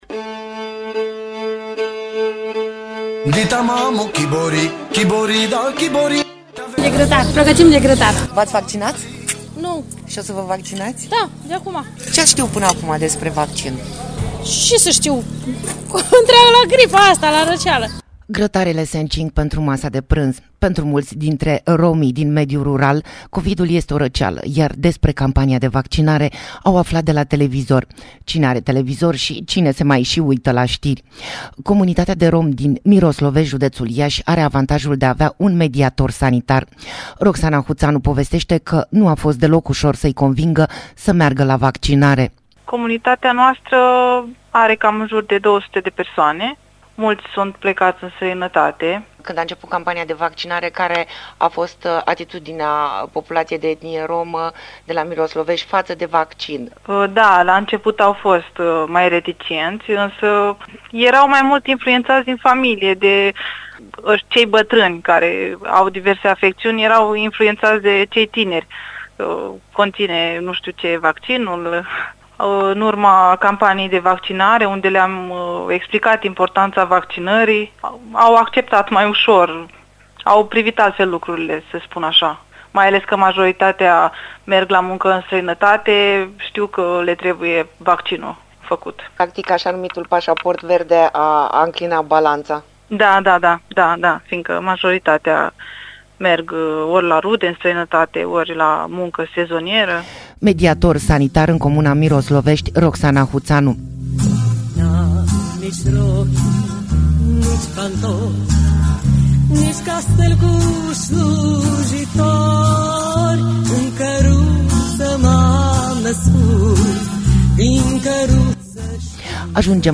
Reportaj-vaccinare-romi.mp3